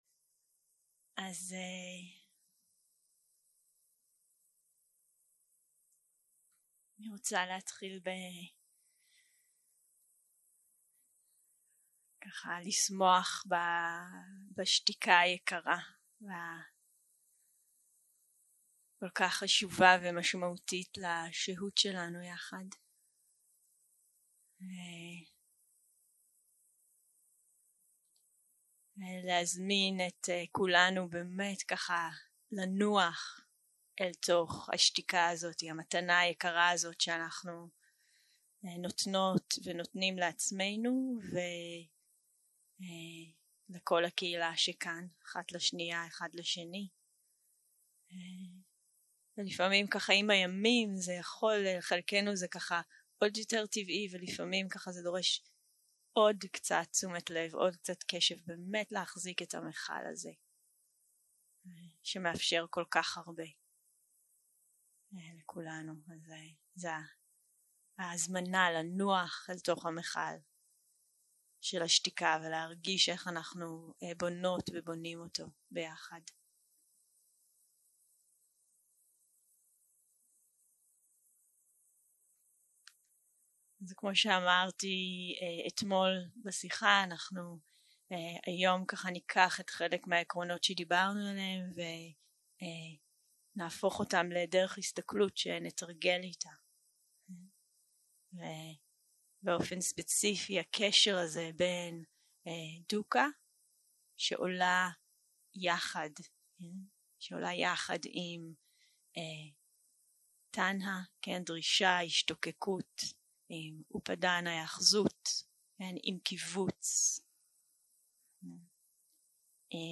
יום 5 - הקלטה 15 - בוקר - הנחיות למדיטציה - דרך ההסתכלות של דוקהה, שחרור ההאחזות 0:00 0:00 סוג ההקלטה: סוג ההקלטה: שיחת הנחיות למדיטציה שפת ההקלטה: שפת ההקלטה: עברית